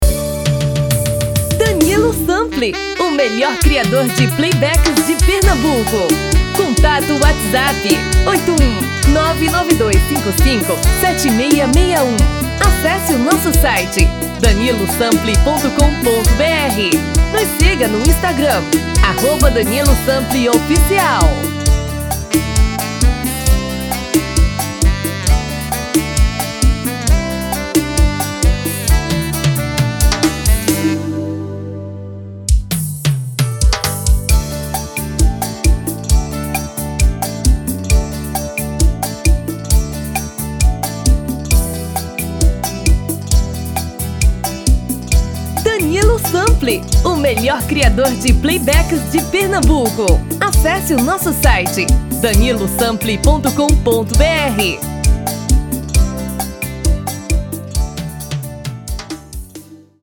RITMO: Arrocha / Seresta
TOM: Feminino (Original)